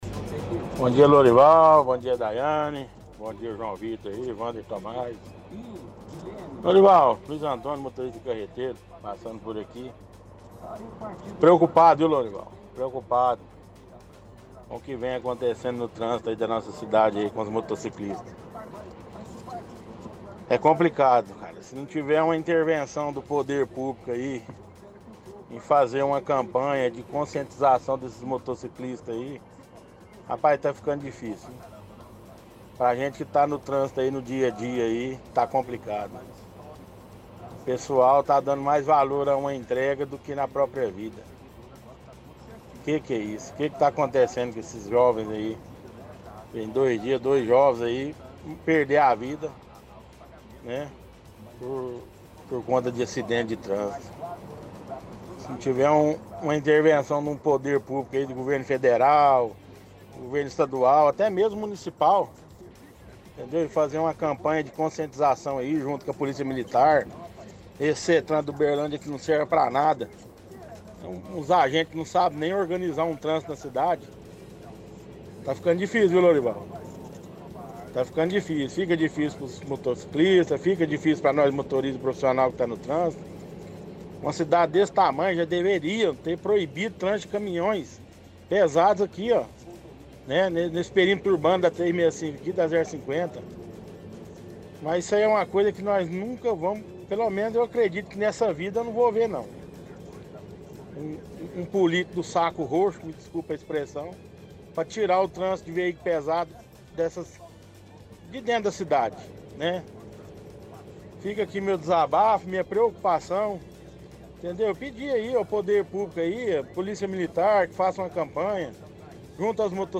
– Ouvinte reclama do trânsito em Uberlândia, pedindo que os governos federal, estadual e municipal façam algo. Diz que a Settran não serve para nada e reclama de trânsito de veículos pesados por dentro da cidade.